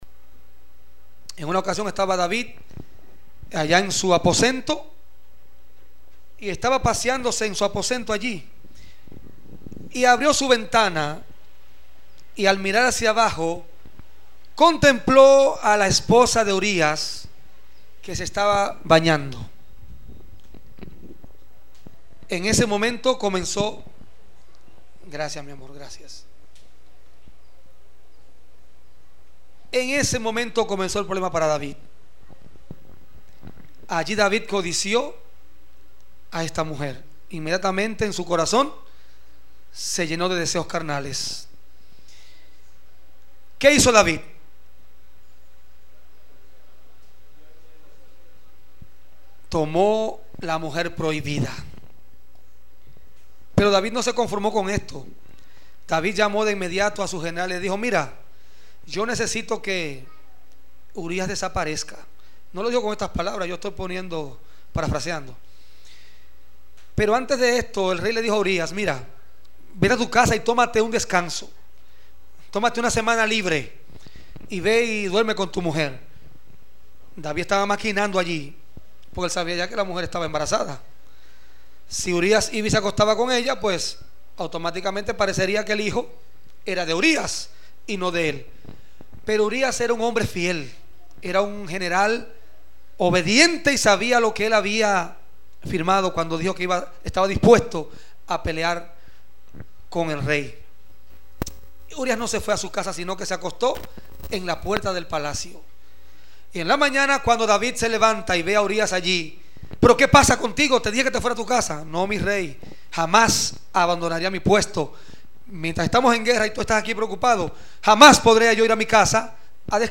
culto divino